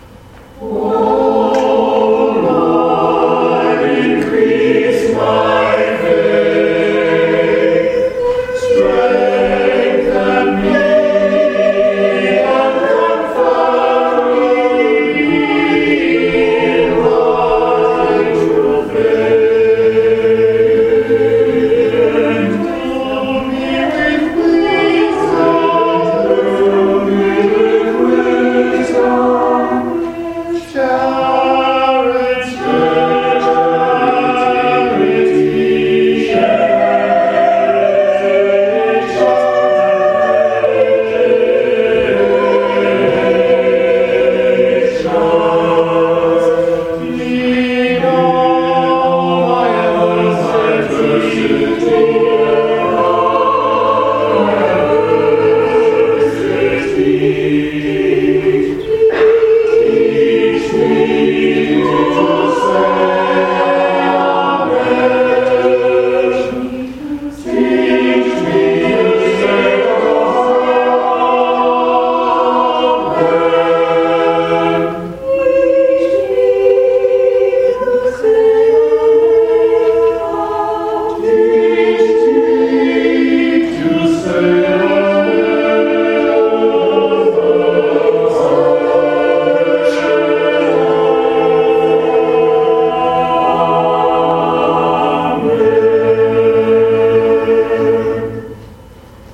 O Lord, Increase My Faith Orlando Gibbons MCC Senior Choir Offertory Anthem, Sunday November 23, 2014 Download file O Lord Increase My Faith
Offertory Anthem, Sunday November 23, 2014